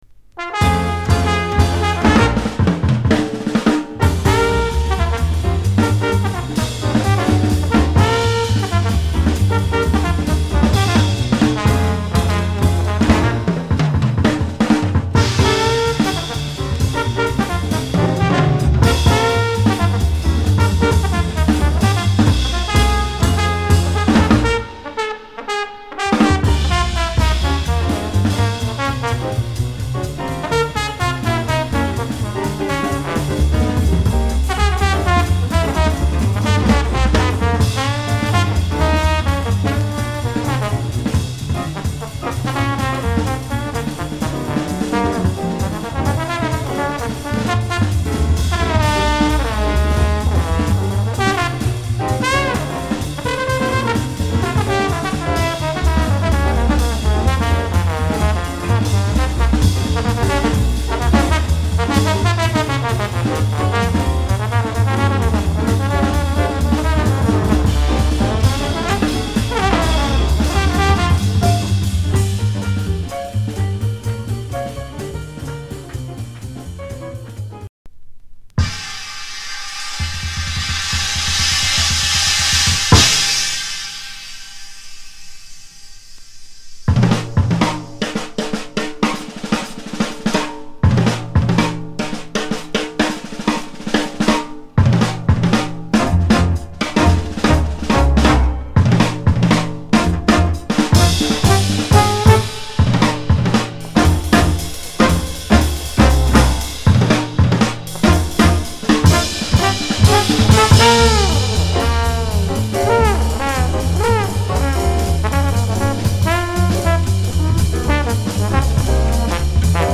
discription:Stereo
Format：LP